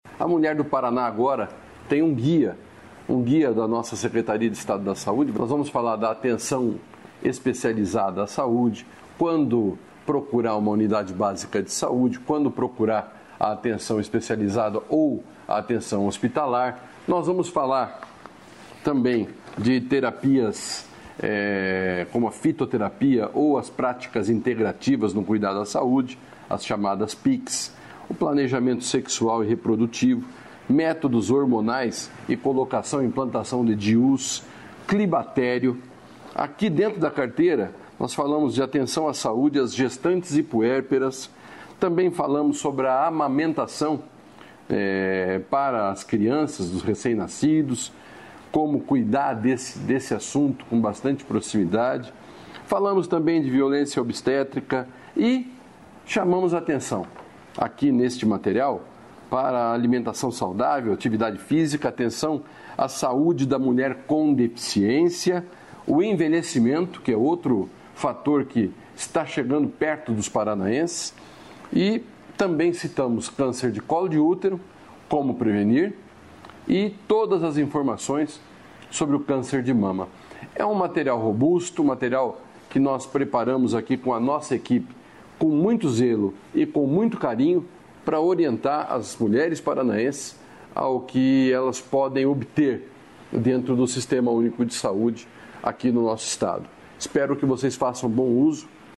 Sonora do secretário da Saúde, Beto Preto, sobre a cartilha da mulher